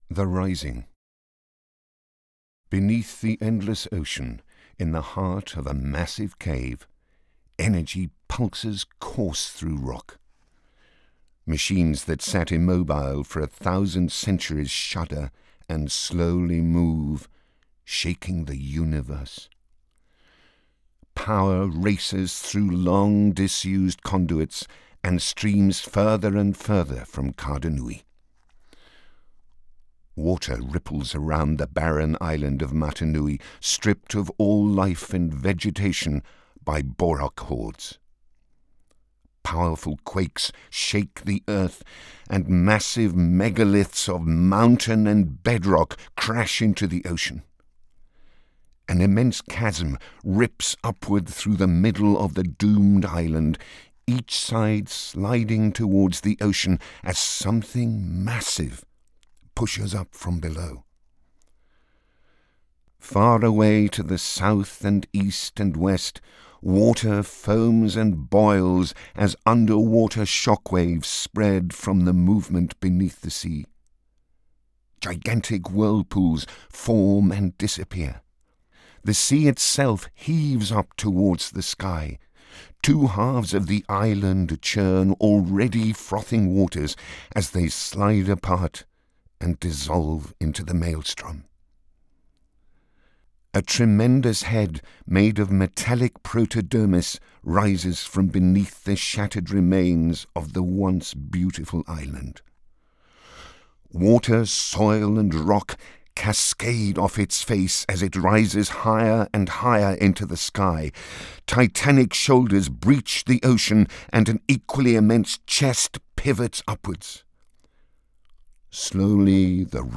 Bionicle_The_Rising_VO.wav